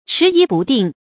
遲疑不定 注音： ㄔㄧˊ ㄧˊ ㄅㄨˋ ㄉㄧㄥˋ 讀音讀法： 意思解釋： 猶言遲疑不決。